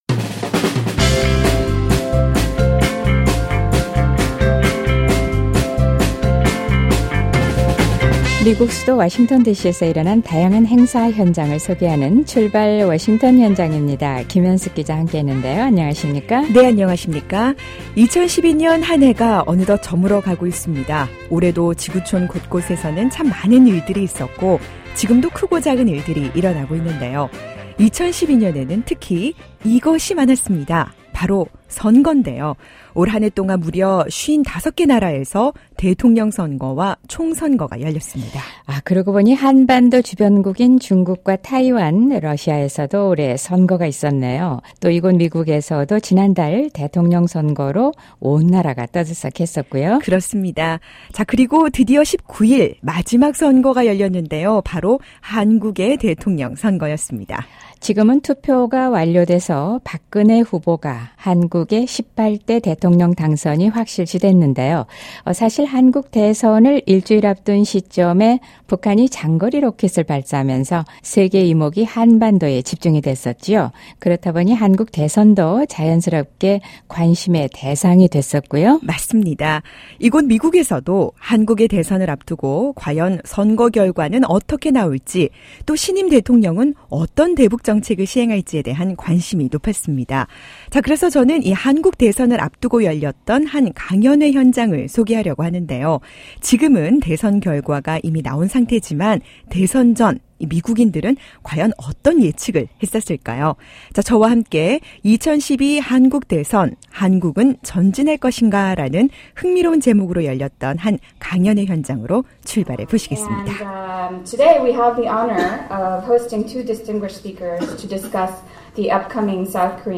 그리고 55번째 마지막 대선은 바로 19일, 한국 대통령 선거였습니다. 한국 대선을 앞두고 워싱턴디씨에서도 대선결과에 따른 미한관계, 대북관계 등에 대한 여러 전망이 나왔는데요, 한국 대선을 바라보는 전문가들과 미국인 젊은이들의 생각을 들어볼 수 있는 강연회 현장을 소개합니다.